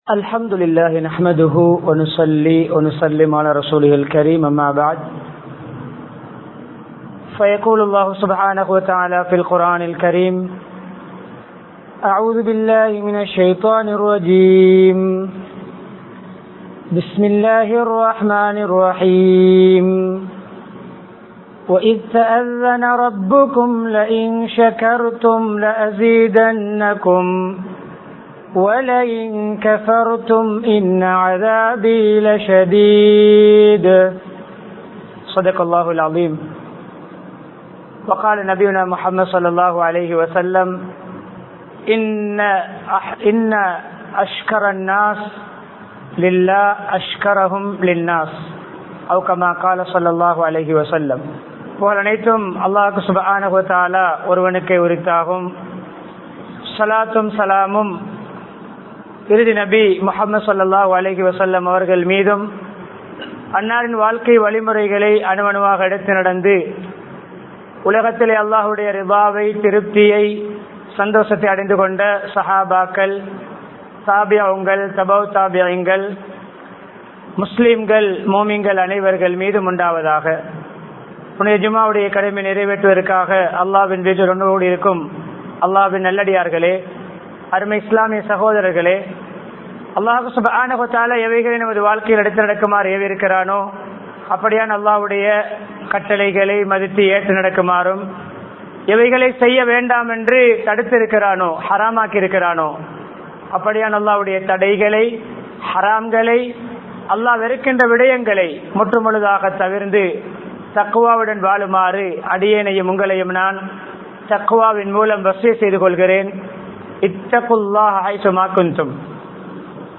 அல்லாஹ்வுக்கு நன்றி செலுத்துவோம் | Audio Bayans | All Ceylon Muslim Youth Community | Addalaichenai